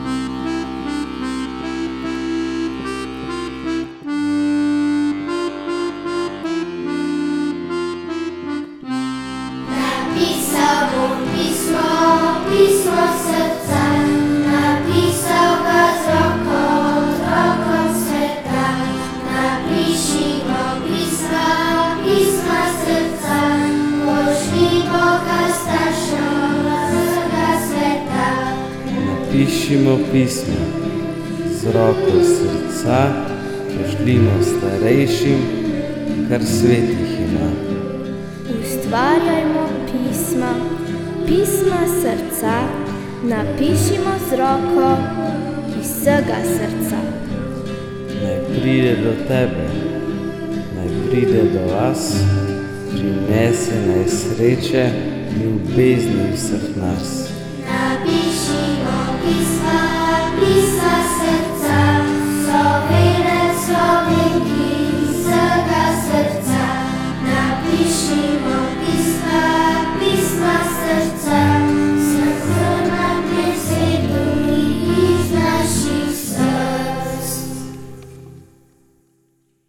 HIMNA
Fundacija-Pismo-Srca-Himna.mp3